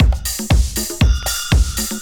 OSH Razz Beat 1_119.wav